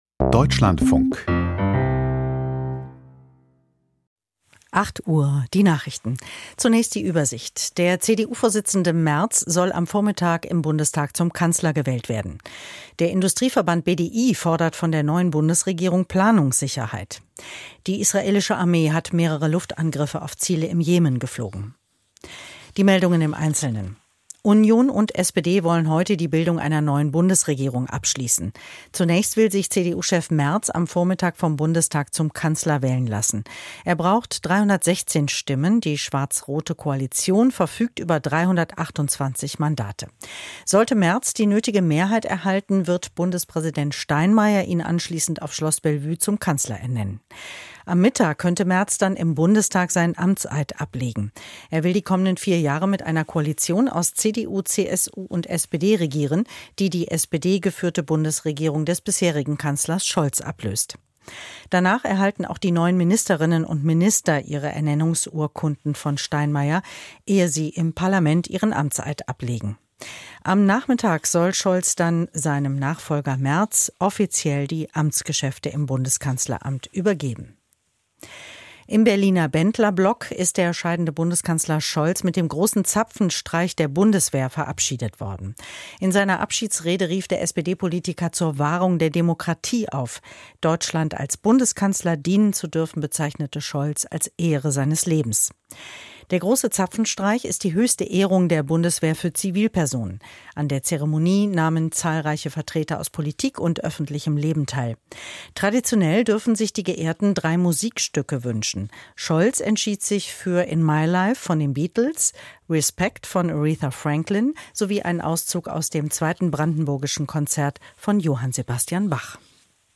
Die Deutschlandfunk-Nachrichten vom 06.05.2025, 08:00 Uhr